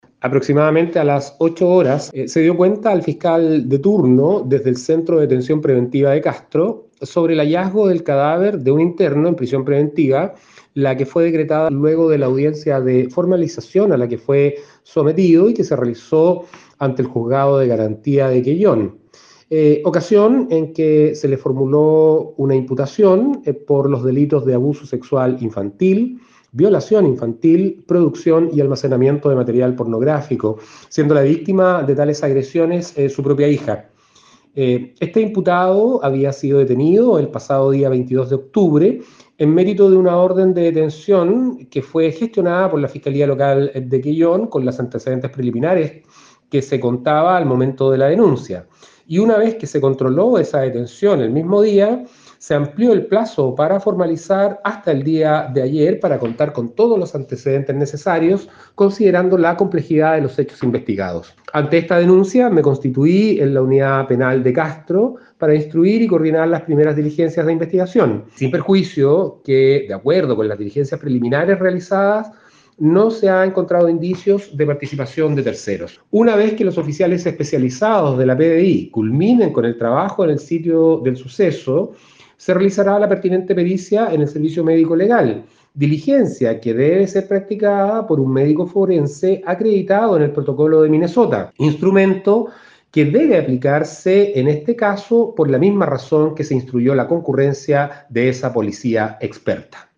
A su vez, desde el ministerio Público el fiscal jefe de Castro Enrique Canales dio tambièn cuenta de esta muerte del imputado de 34 años de edad.